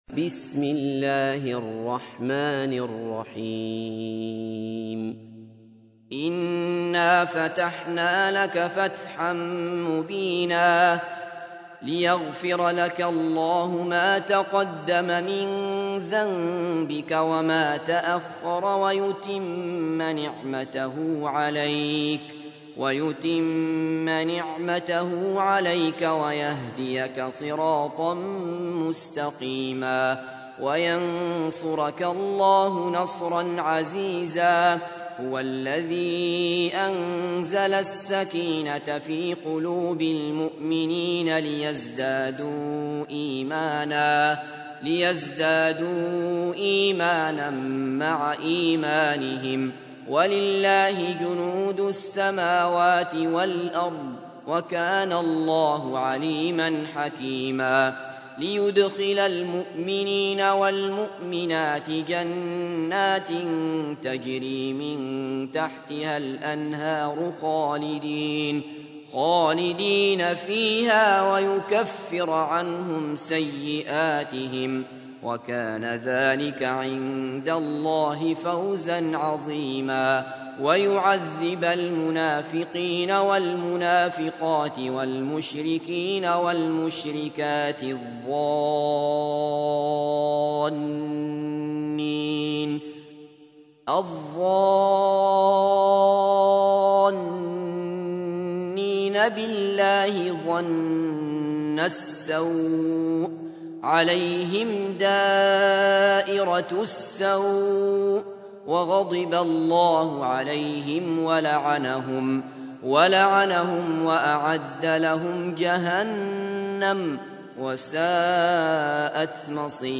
تحميل : 48. سورة الفتح / القارئ عبد الله بصفر / القرآن الكريم / موقع يا حسين